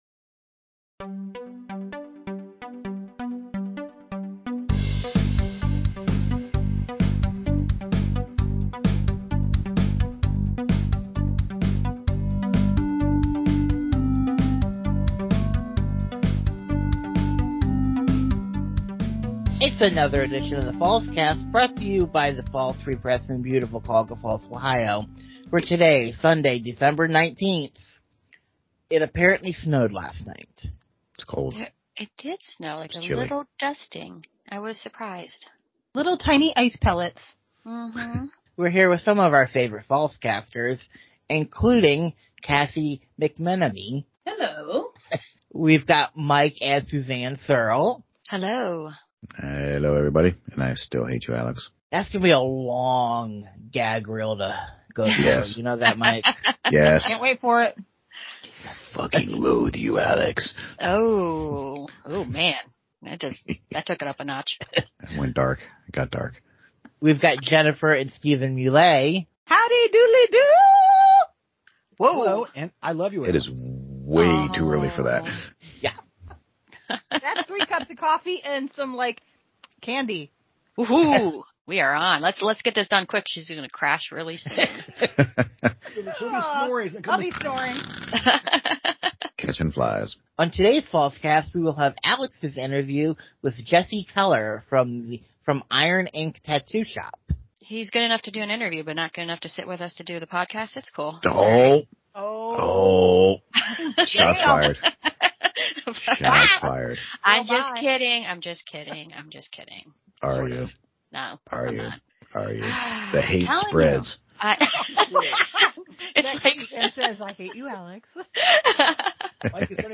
Twas the week before Christmas, and all through the Skypes, the Fallscasters were restless, pondering the puns to make just in spite. The news wasn’t heavy, but no fret they were there, with a few headlines worthy, and an interview for which to care.